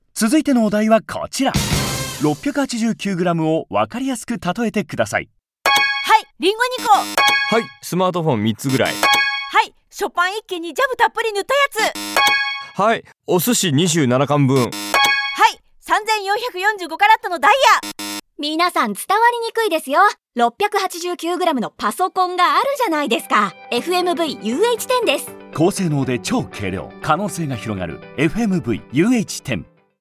ユニークな例えを交えながら、身近なものをどんどん想起させることで、音声CMに対する興味関心を高めることを狙っています。